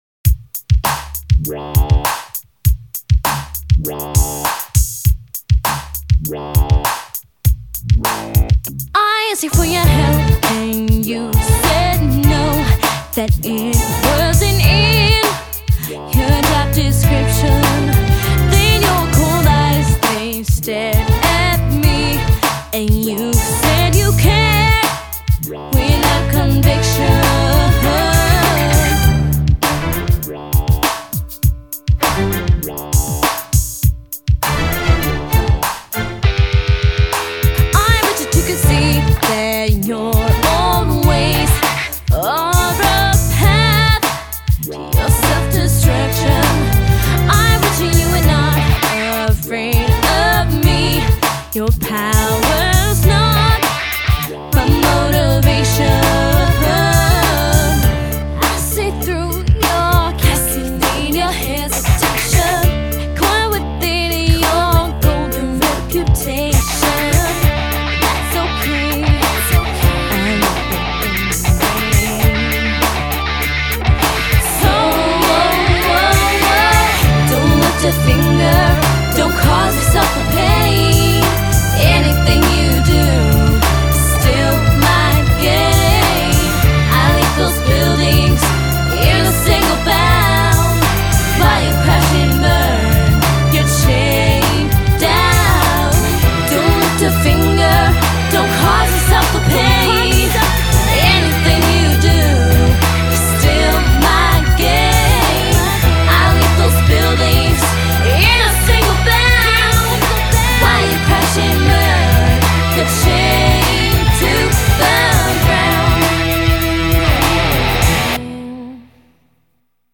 BPM100--1